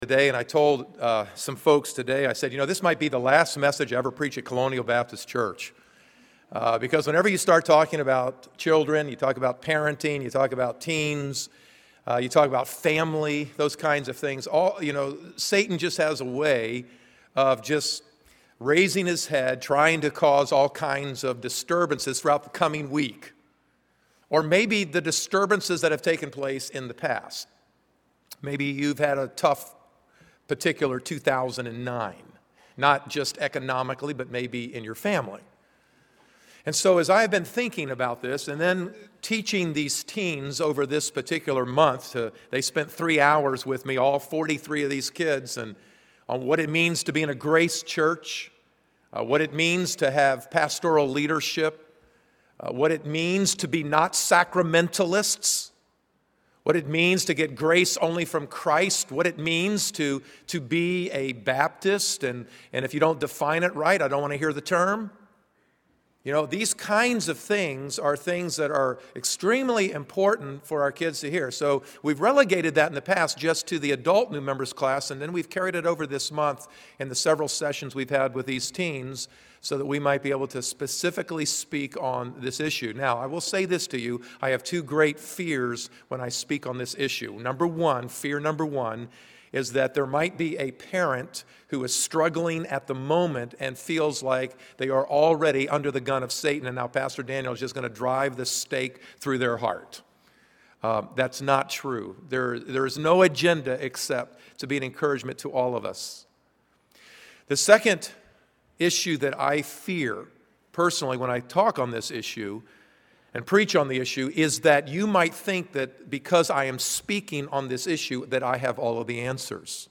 December 2009 Sermons